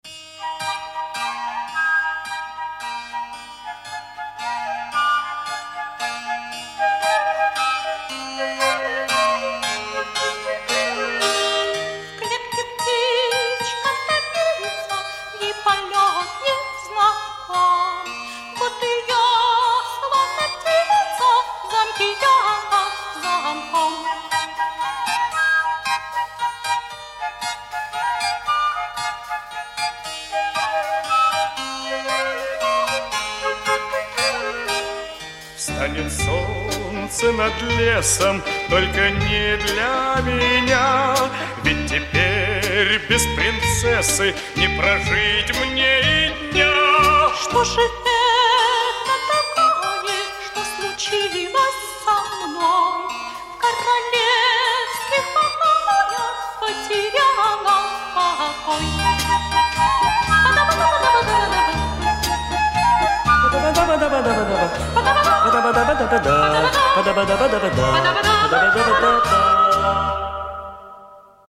• Категория: Детские песни
песни из мультфильмов, советские детские песни